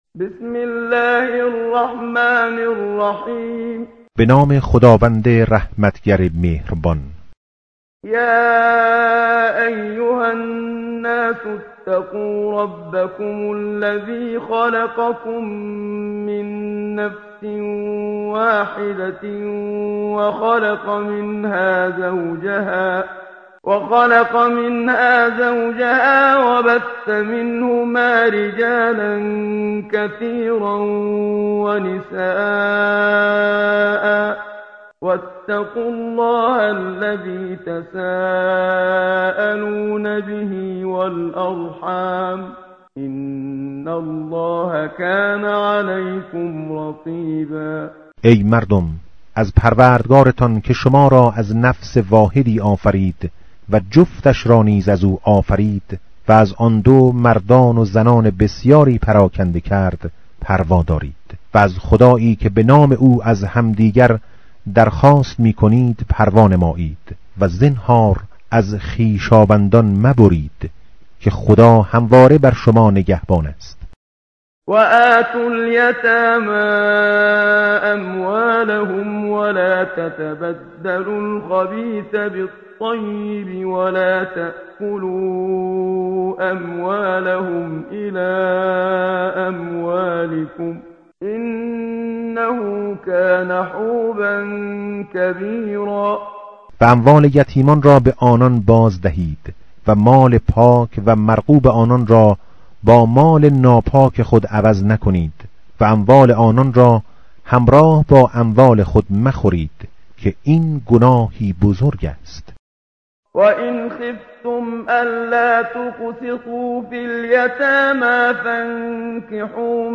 tartil_menshavi va tarjome_Page_077.mp3